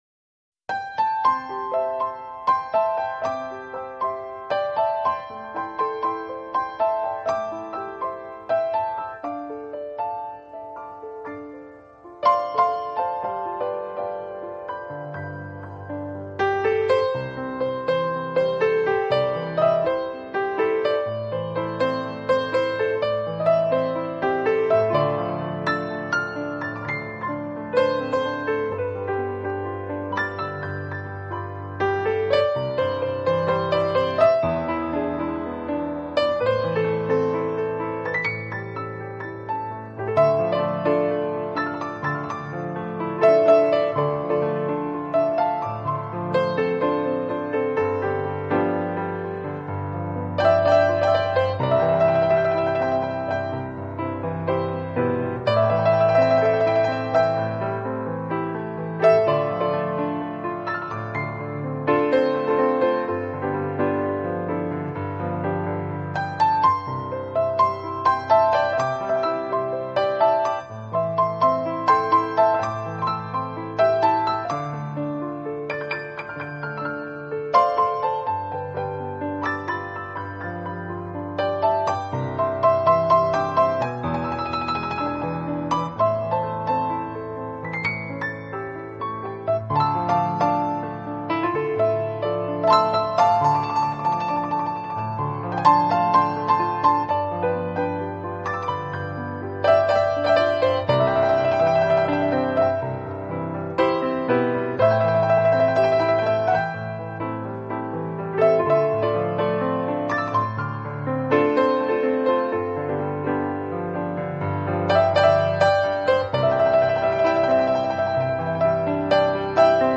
这是一套非常经典的老曲目经过改编用钢琴重新演绎的系列专辑。
本套CD全部钢琴演奏，